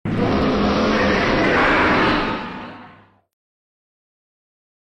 File:Bagan Roar (Godziban and Godzilla Battle Line).mp3
Bagan_Roar_(Godziban_and_Godzilla_Battle_Line).mp3